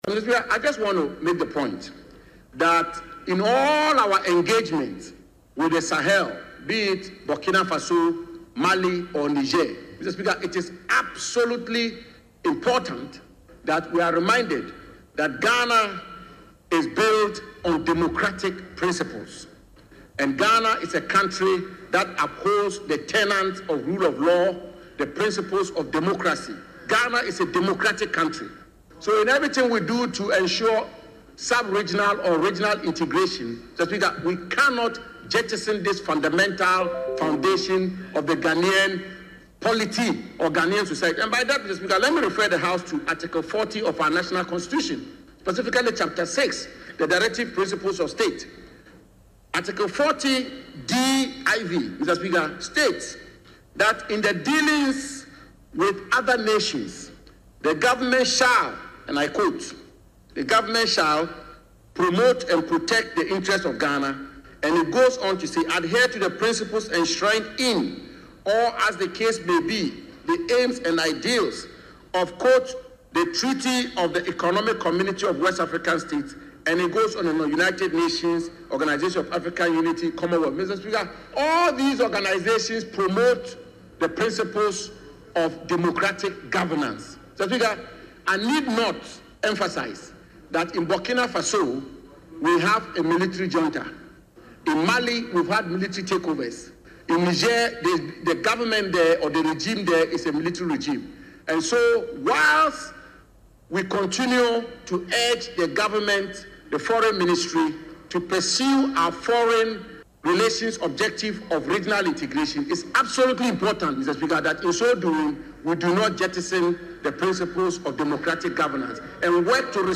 Hon. Jinapor made this statement in Parliament while seconding the motion for the approval of the budget estimate for the Ministry of Foreign Affairs and Regional Integration.